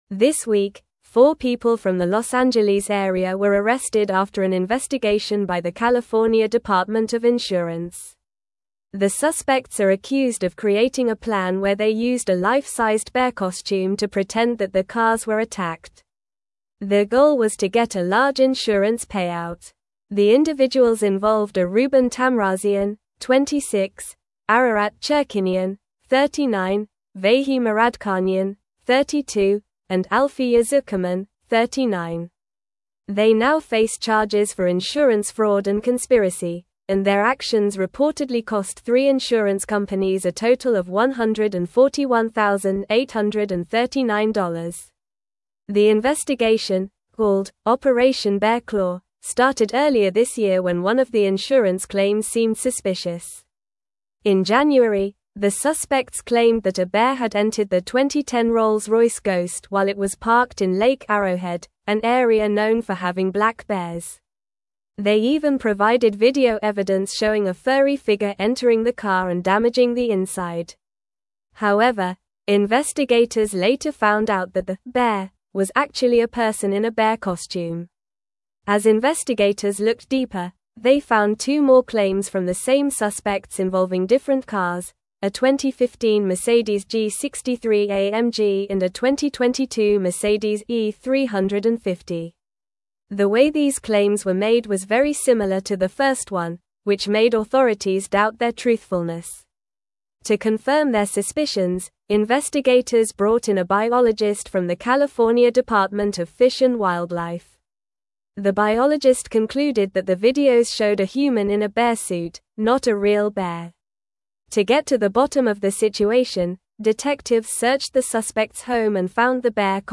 Normal
English-Newsroom-Upper-Intermediate-NORMAL-Reading-Suspects-Arrested-for-Staging-Bear-Attack-Insurance-Fraud.mp3